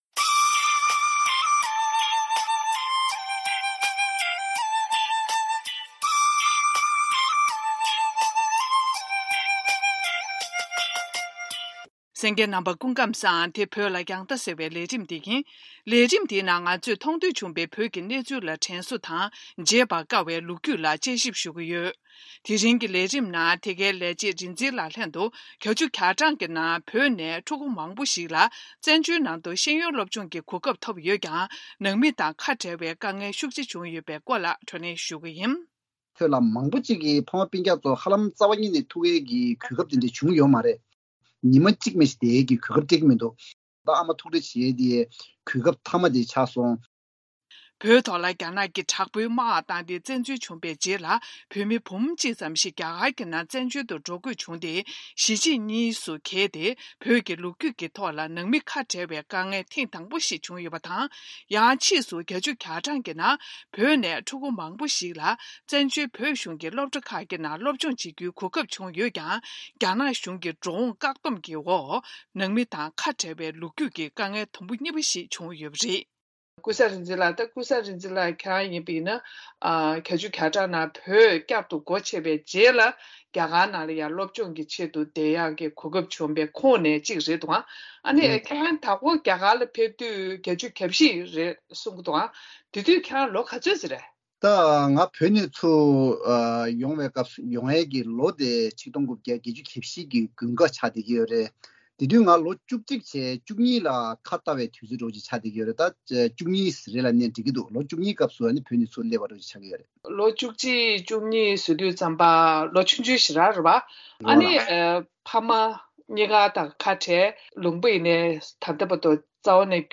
Tibet Remembrance – Interview